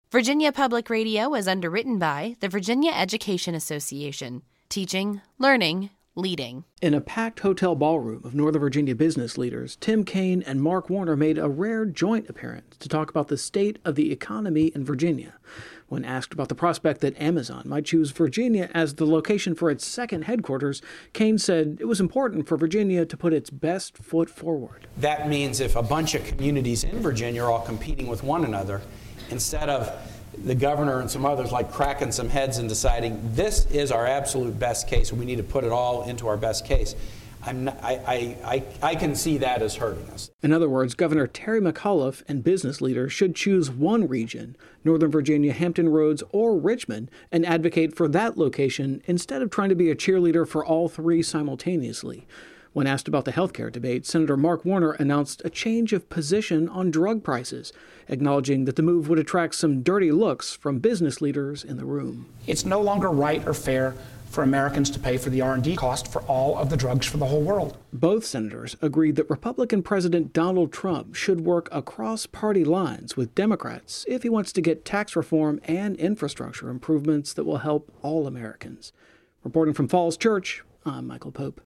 At a packed hotel ballroom of Northern Virginia’s business leaders, Tim Kaine and Mark Warner made a rare joint appearance Monday to talk about the state of the economy in Virginia.